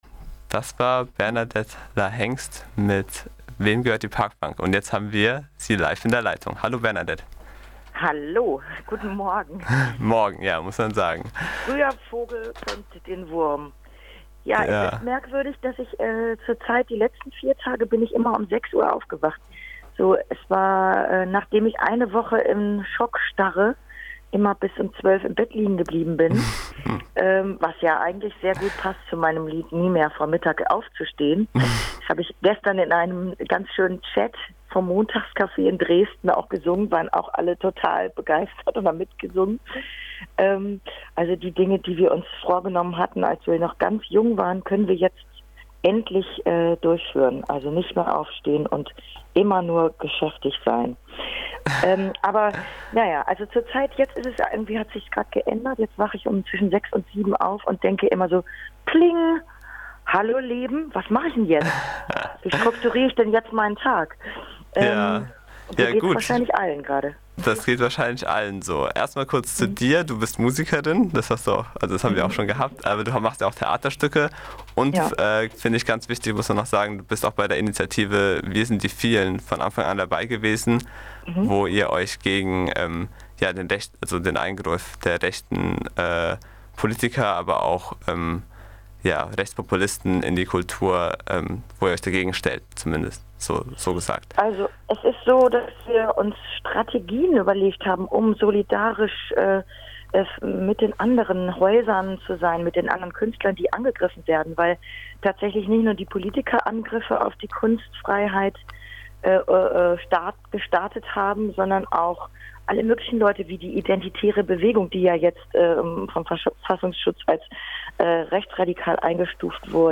Interviews
der Finissage von Brücken Bauen in der Freiburger Volkshochschule